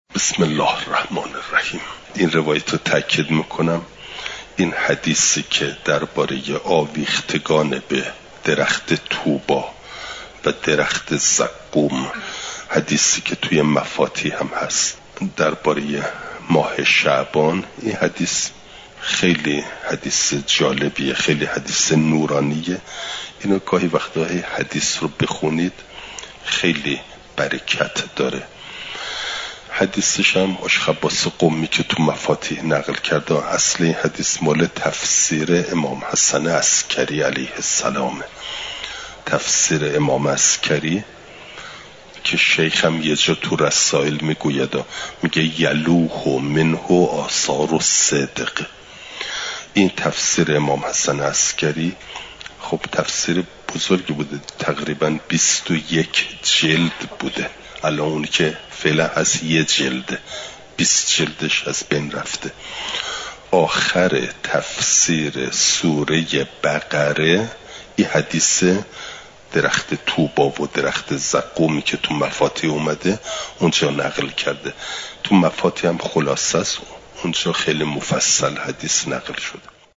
سخن کوتاه
شنبه ۱۳ بهمن‌ماه ۱۴۰۳، حرم مطهر حضرت معصومه سلام ﷲ علیها